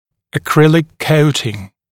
[ə’krɪlɪk ][э’крилик]акриловое покрытие